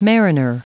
Prononciation du mot mariner en anglais (fichier audio)